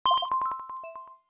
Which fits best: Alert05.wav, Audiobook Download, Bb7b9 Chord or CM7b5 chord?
Alert05.wav